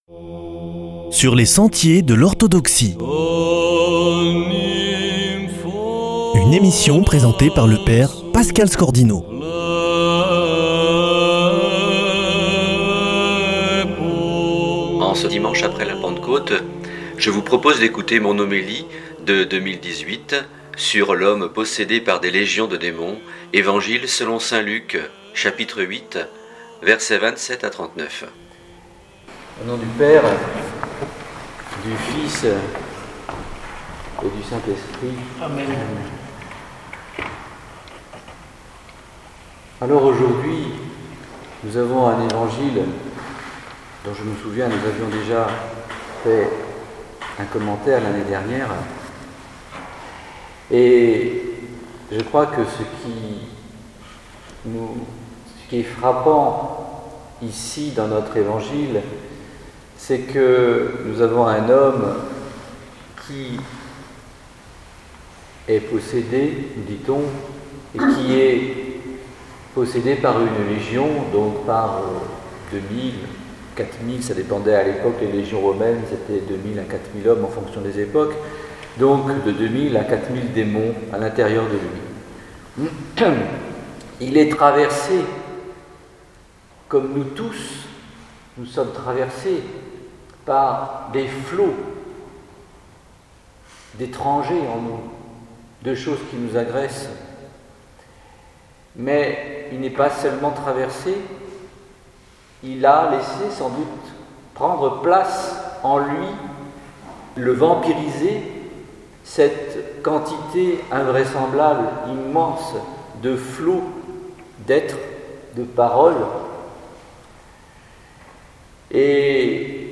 Homélie 2018 du 23e dimanche ap. Pentecôte / le possédé par légion de démons